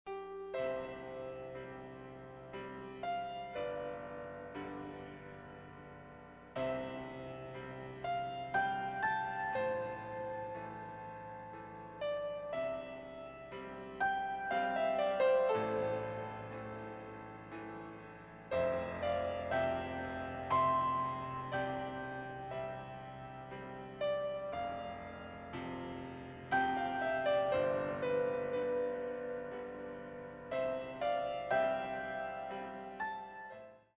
33 Piano Selections.